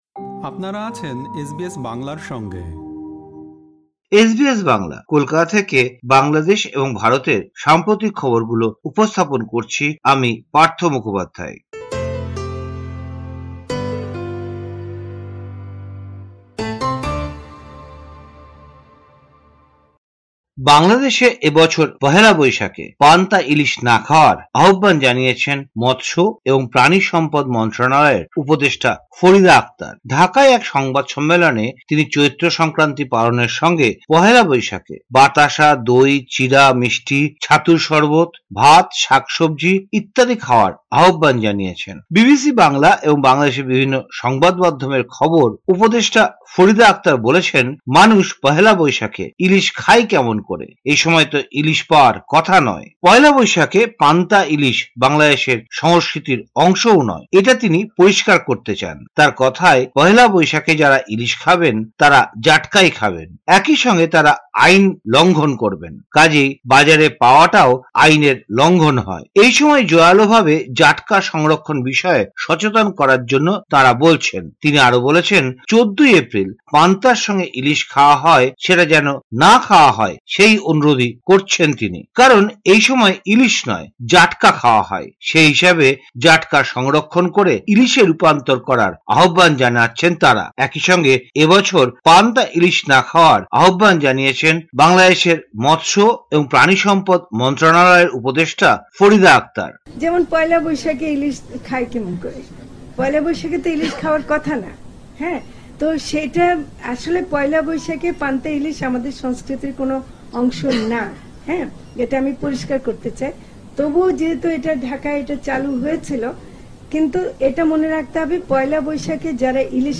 সম্পূর্ণ প্রতিবেদনটি শুনতে উপরের অডিও-প্লেয়ারটিতে ক্লিক করুন।